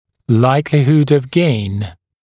[‘laɪklɪhud əv geɪn][‘лайклихуд ов гейн]вероятность получения положительных результатов, вероятность улучшения